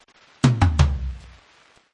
Tesla Lock Sound Drums – Toms Down
(This is a lofi preview version. The downloadable version will be in full quality)
JM_Tesla_Lock-Sound_Drums-Toms-Down_Watermark.mp3